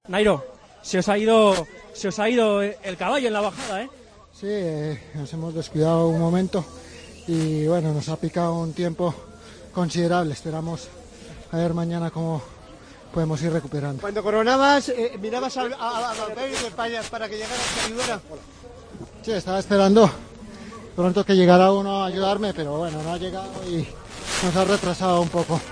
El ciclista colombiano ha atendido a los medios de comunicación al término de la octava etapa y ha reconocido la estrategia de Chris Froome: "Nos ha picado un tiempo considerable. Veremos mañana cómo podemos ir recuperando".